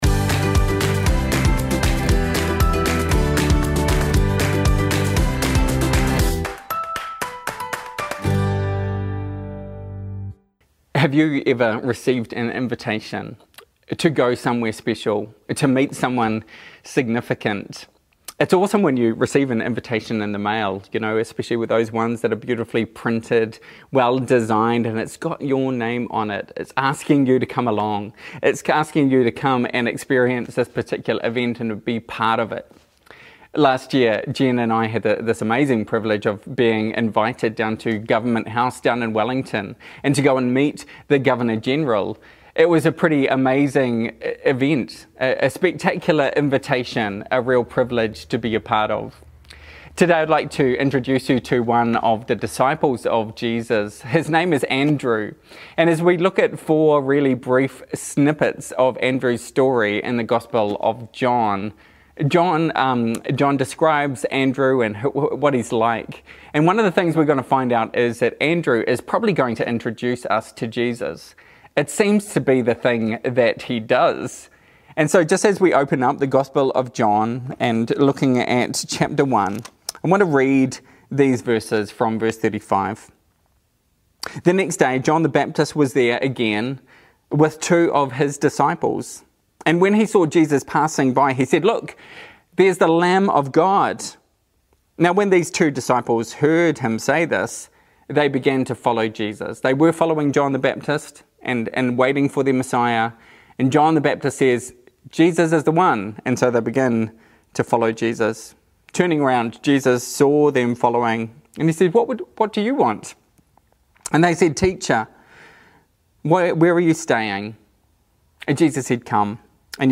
The writer of Hebrews urges followers of Jesus, “let us” continue in three specific things. Three things that are just as vital today, which is why we’ll be preaching from these verses on Sunday.